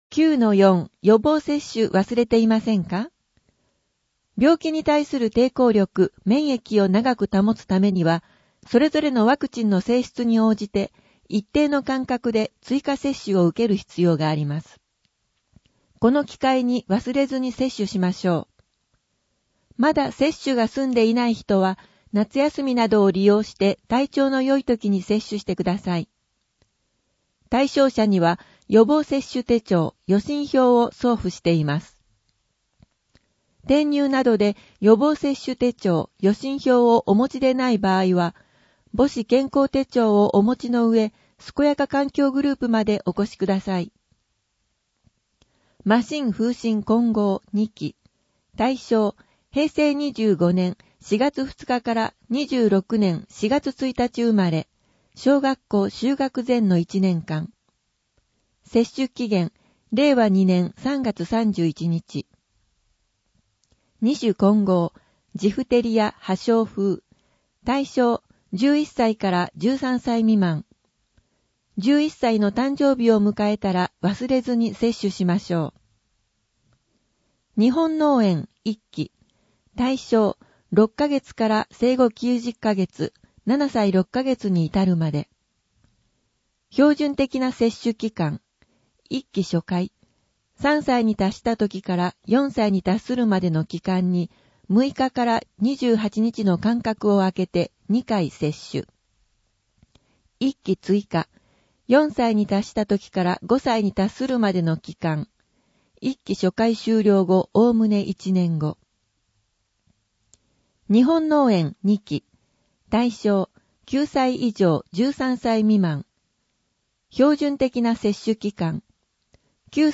声の「広報はりま」8月号
声の「広報はりま」はボランティアグループ「のぎく」のご協力により作成されています。